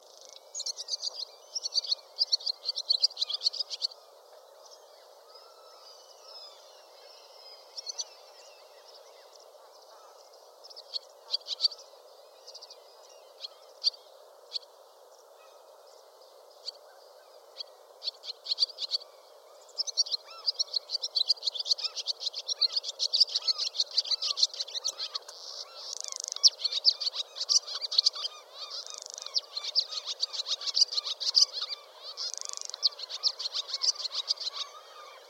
Barn Swallow utters rapid twittering interspersed with harsh, raucous sounds, and creaking notes at the end. This song is often uttered when the bird perches on wires or exposed branches.
The usual contact call is a clear “witt-witt-witt”, and the alarm call is a double shrill “tsuii”, accompanied by fast flights at intruder or predator.
HIRUNDO-RUSTICA-.mp3